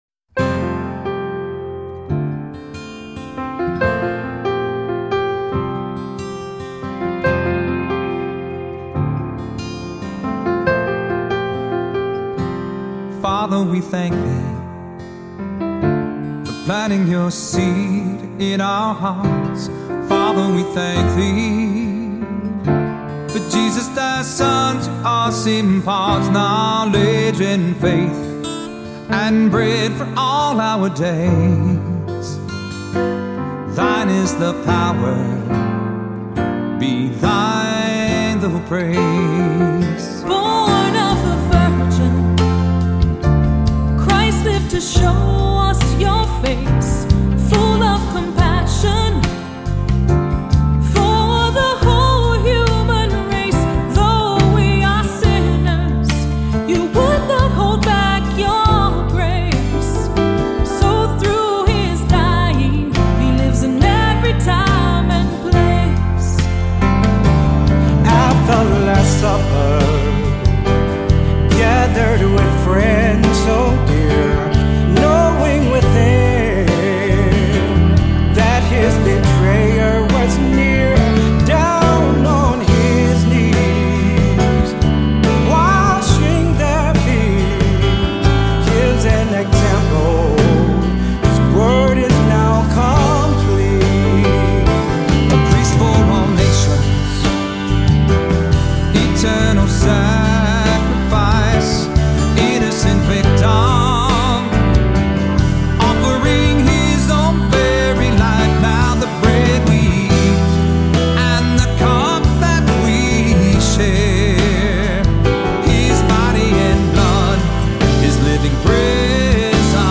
Voicing: Three-part equal